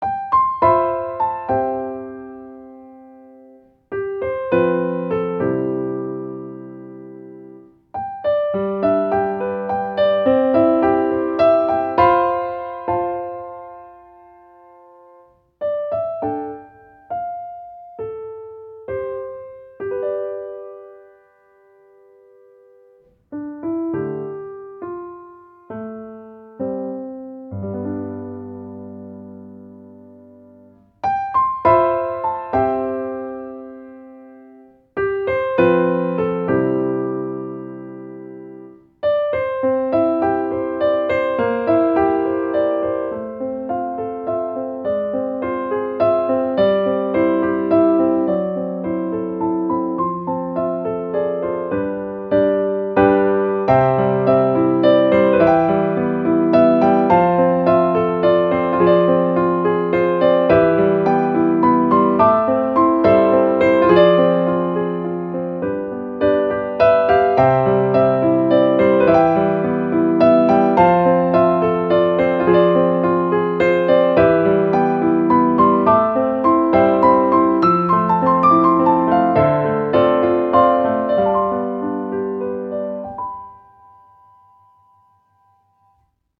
ogg(R) - 切ない 感情的 ドラマティック